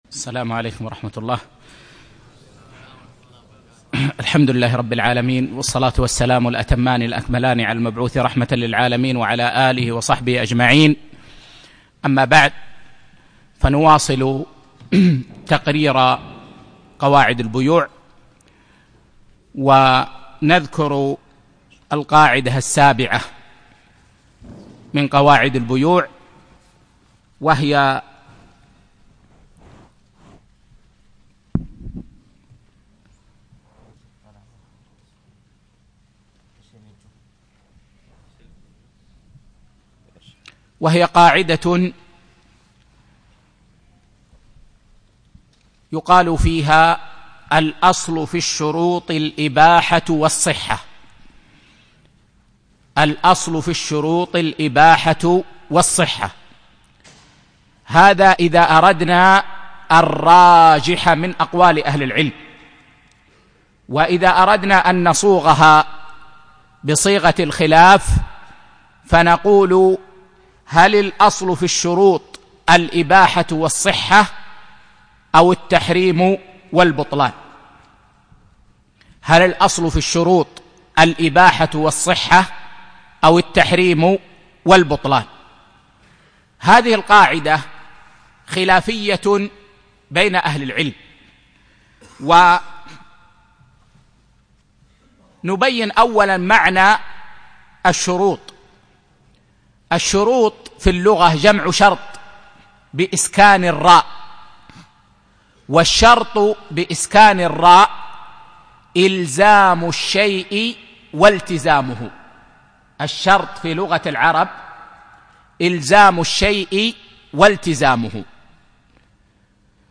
7- قواعد في المعاملات المالية (1) - الدرس السابع